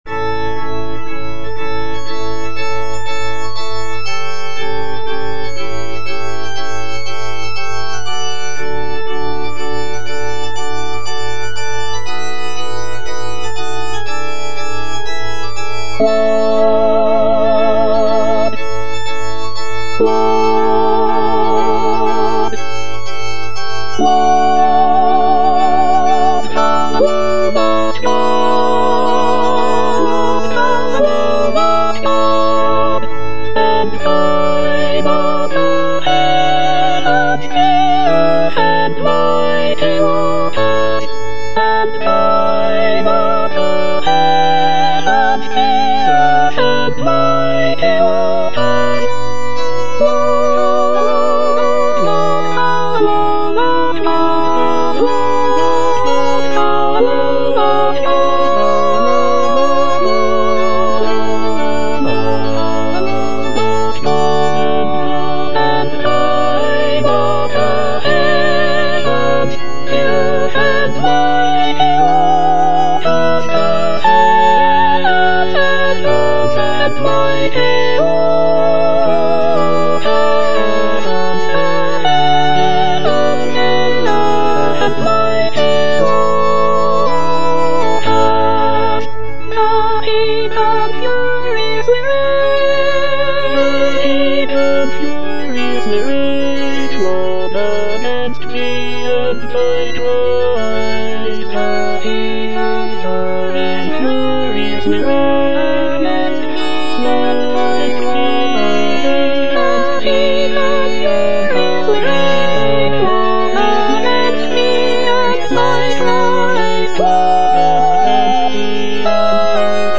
Lord, Thou alone art God - Soprano (Emphasised voice and other voices) Ads stop: auto-stop